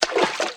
STEPS Water, Walk 04.wav